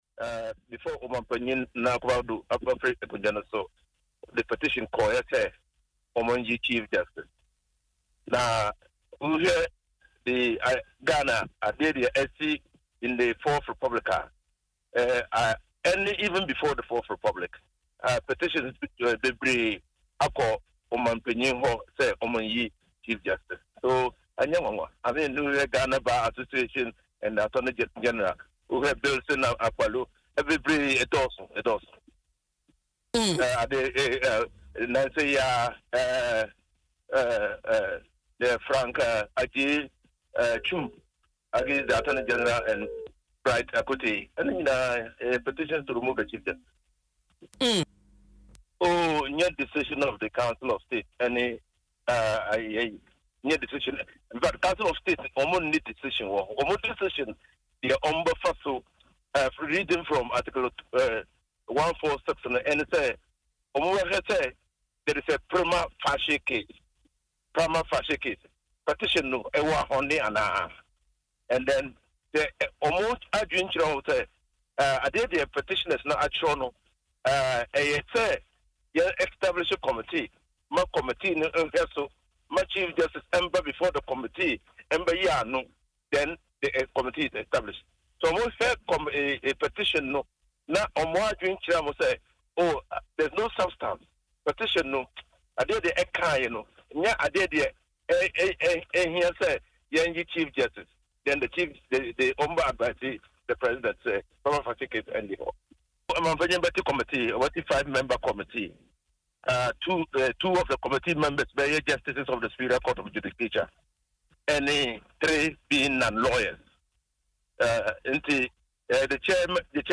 Speaking on Adom FM’s Dwaso Nsem, the lawyer noted that this is not the first time petitions have been submitted seeking the removal of Chief Justices.